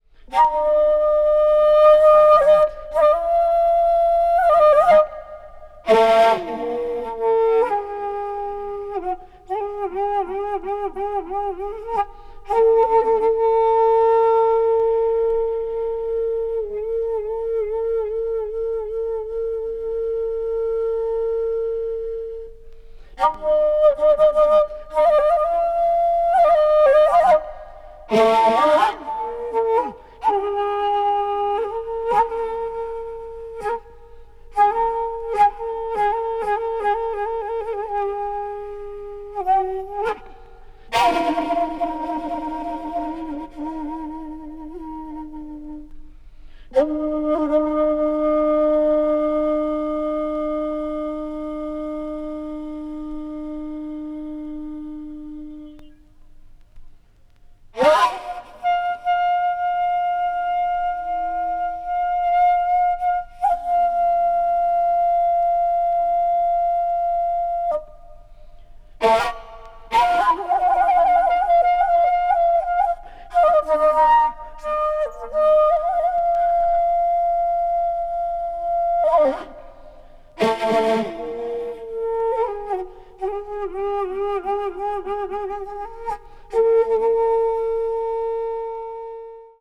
media : EX/EX(わずかにチリノイズが入る箇所あり)
アヴァンギャルド・ピース。